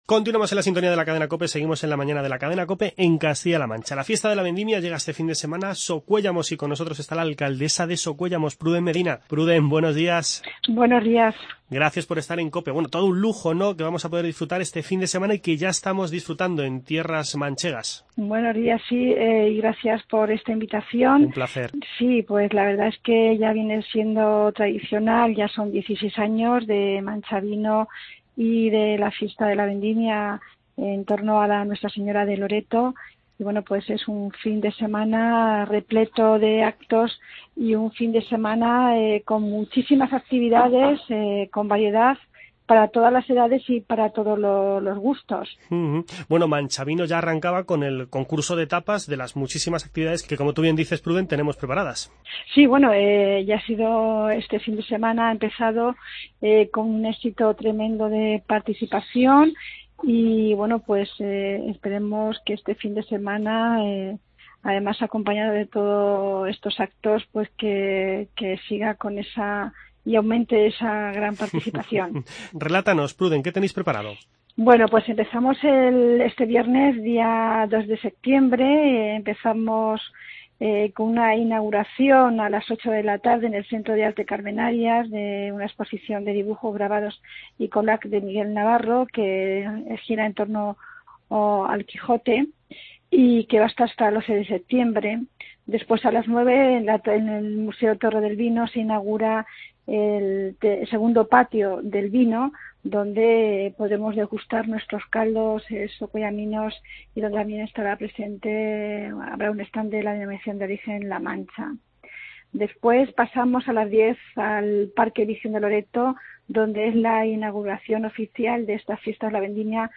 Escuche la conversación en "La Mañana" con la alcaldesa de Socuéllamos, Pruden Medina.